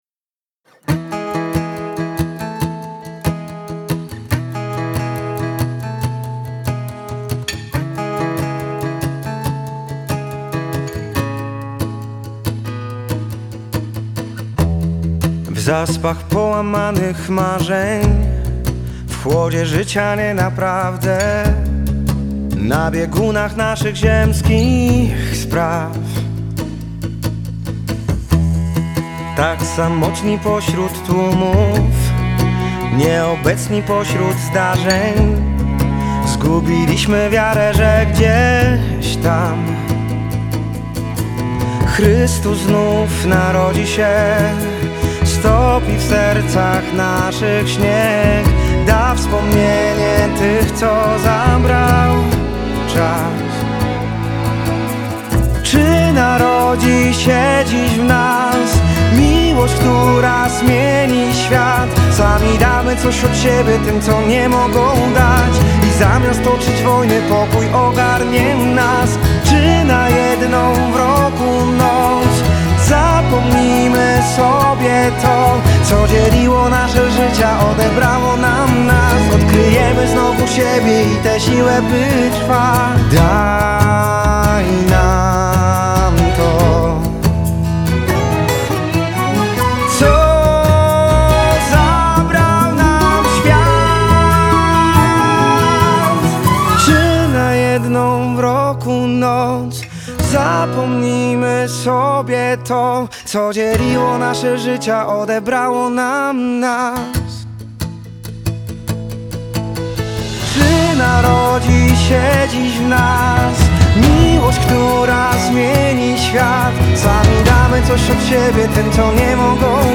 bardzo dobra piosenka świąteczna nastrojowa i rytmiczna